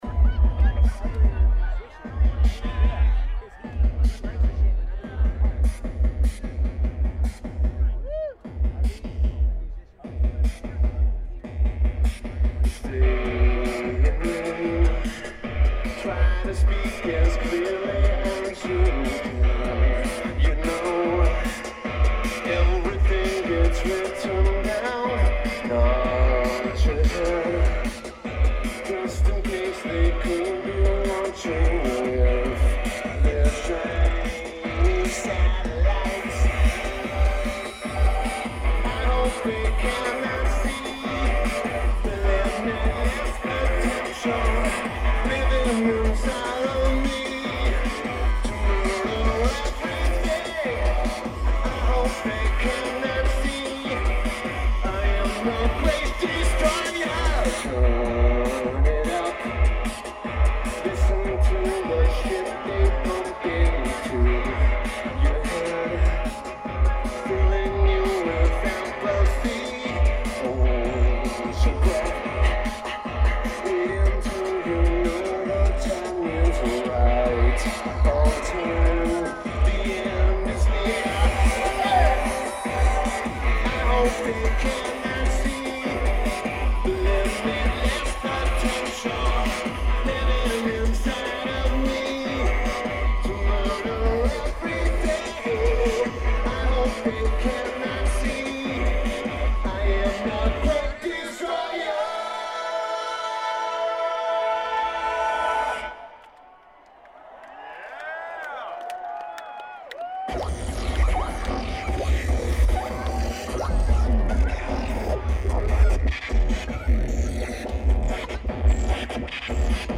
Keyboards/Bass/Backing Vocals
Drums
Guitar